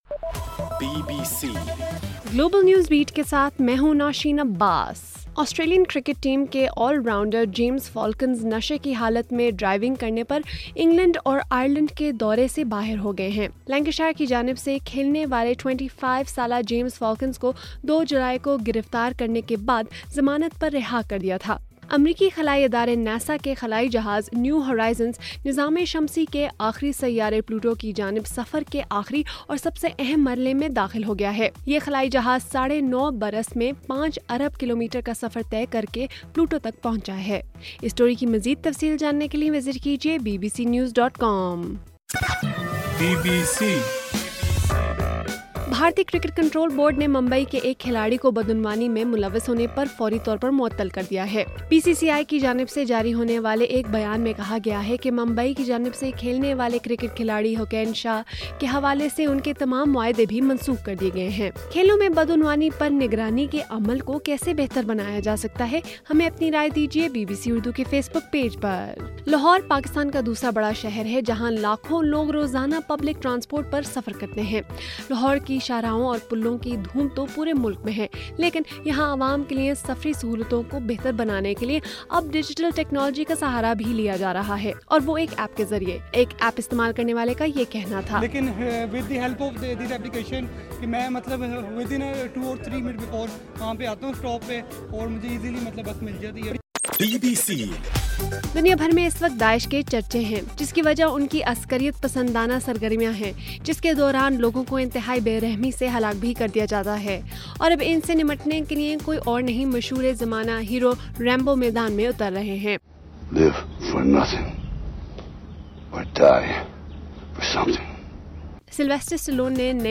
جولائی 14: رات 11 بجے کا گلوبل نیوز بیٹ بُلیٹن